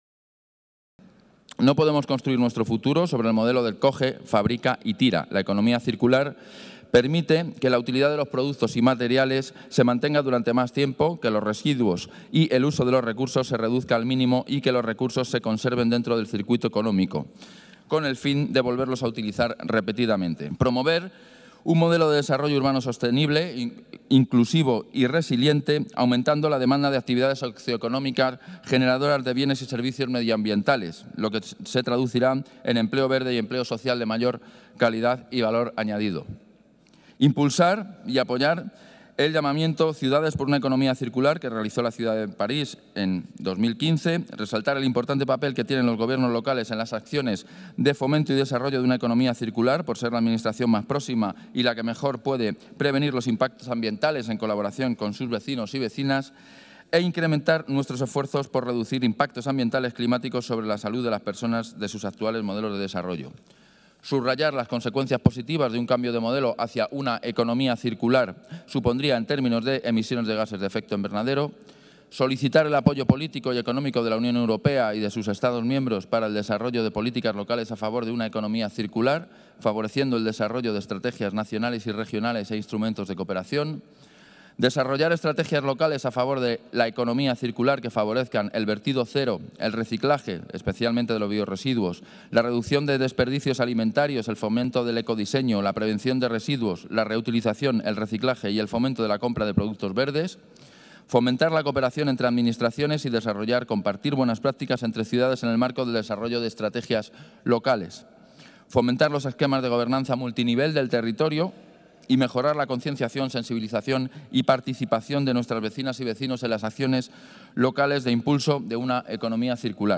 Nueva ventana:El segundo teniente alcalde, Nacho Murgui, hablando de los objetivos de la Economía Circular